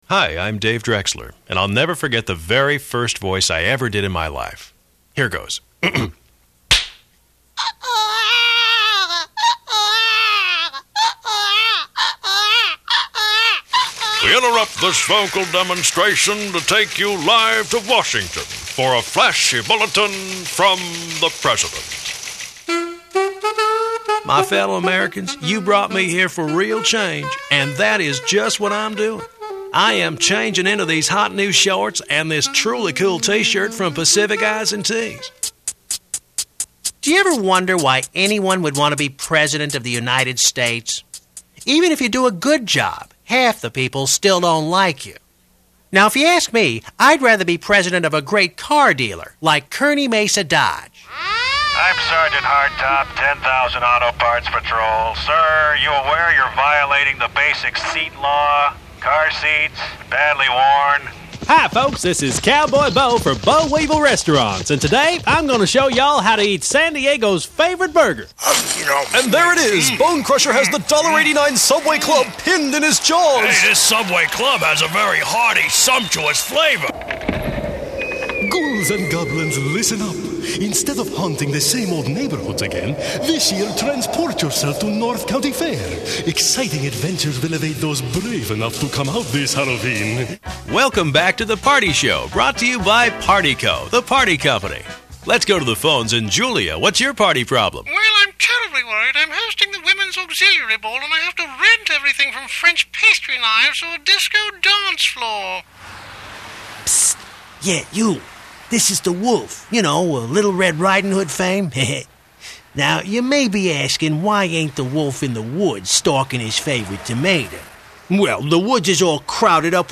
Character Voices
CHARACTER VOICES DEMO